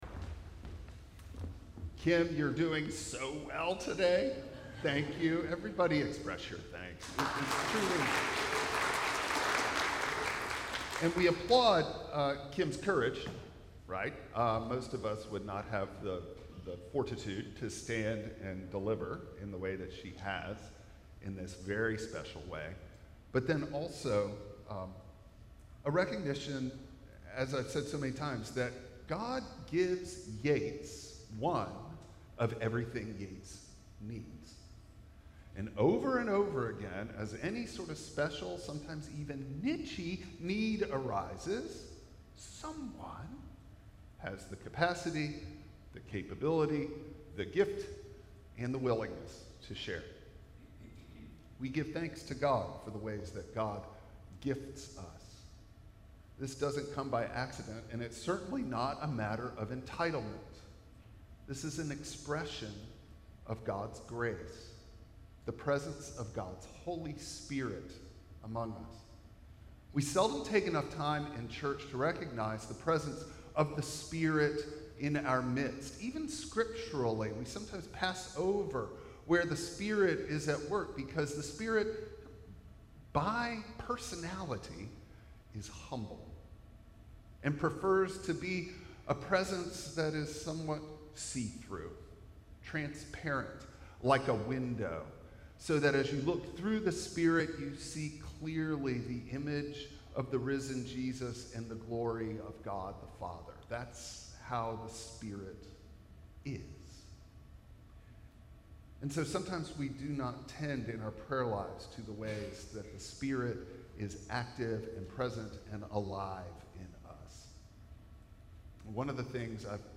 John 20:19-23 Service Type: Traditional Service Bible Text